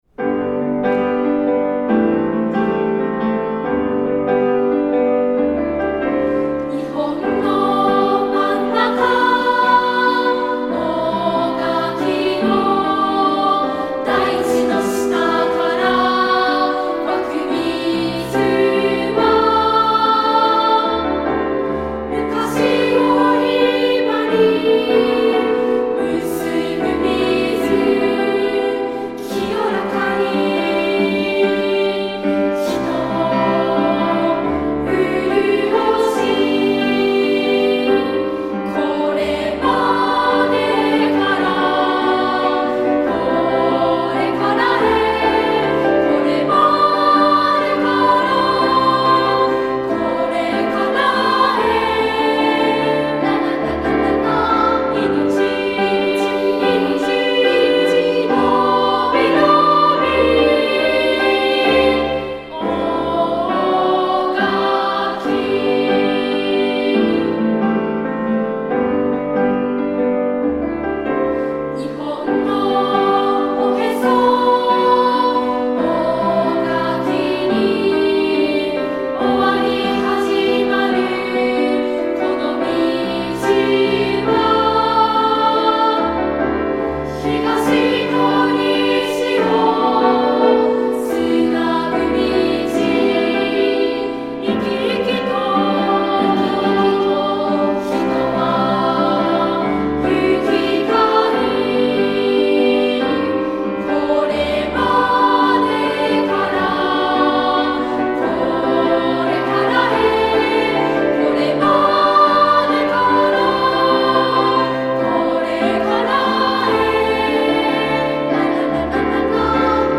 女声二部合唱